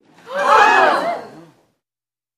Crowd, Medium, In Shock